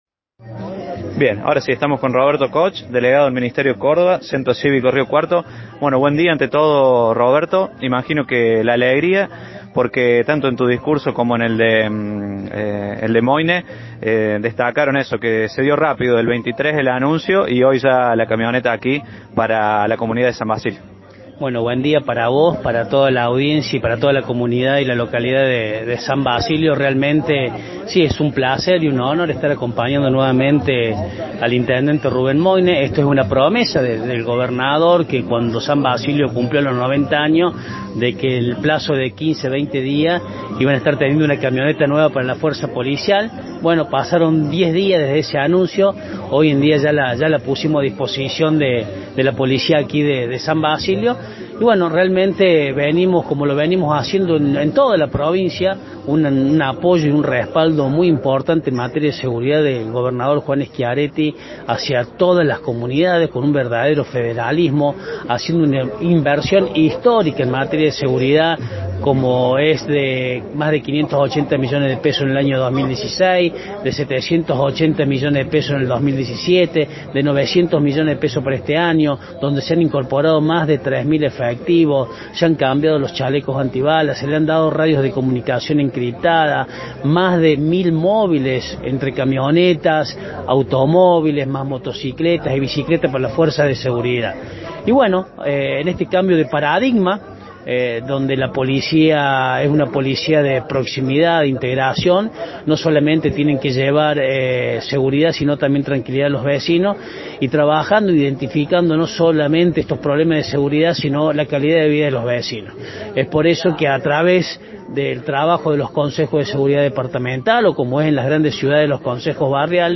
Escuchamos la palabra de Roberto Koch en dialogo con FM Samba 93.1: